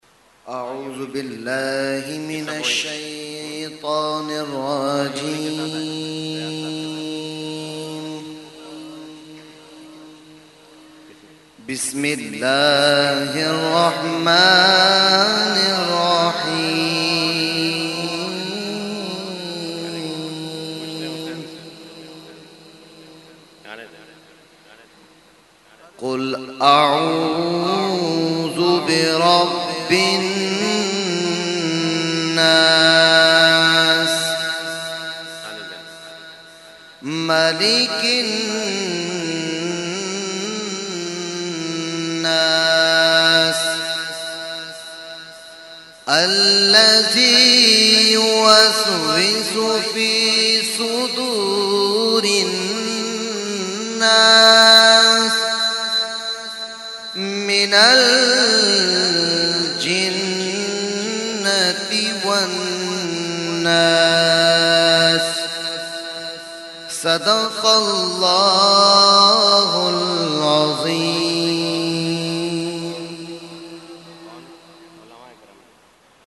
held on 21,22,23 December 2021 at Dargah Alia Ashrafia Ashrafabad Firdous Colony Gulbahar Karachi.
Category : Qirat | Language : ArabicEvent : Urs Qutbe Rabbani 2021-2